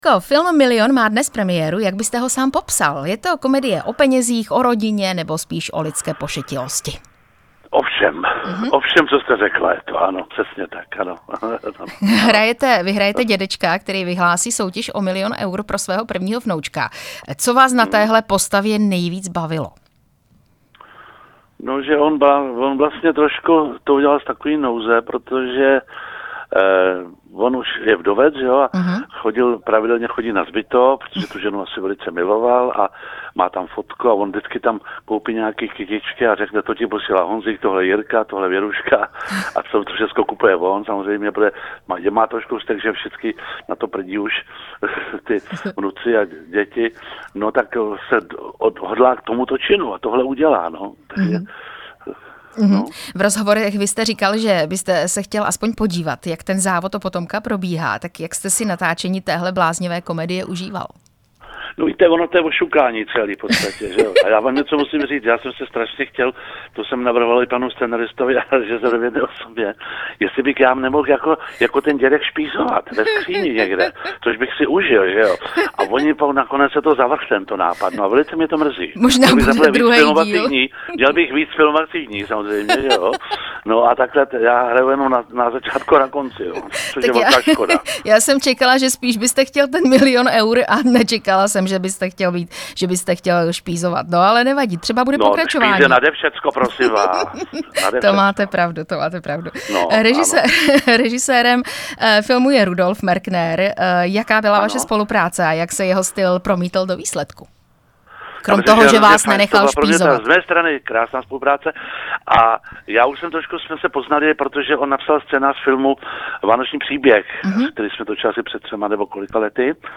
Rozhovor s hercem Jiřím Lábusem